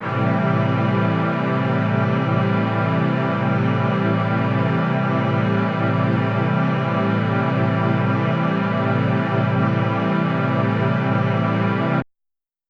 SO_KTron-Ensemble-Amin7.wav